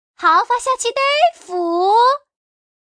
Index of /poker_paodekuai/update/1526/res/sfx/changsha_woman/